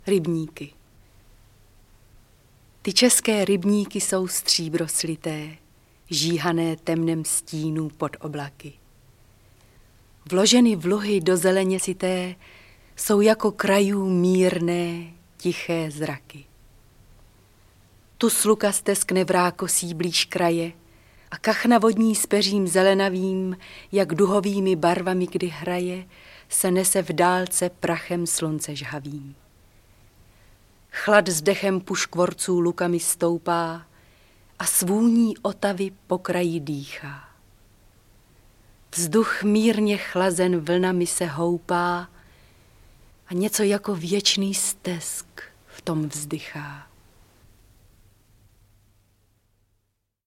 Druhý výběr českých básní ve školní řadě Gramofonového klubu prohlubuje a rozšiřuje možnosti prvého výběru z roku 1960: probouzet poslechem uměleckého přednesu zájem mladých lidí o poezii.
Ukázka z knihy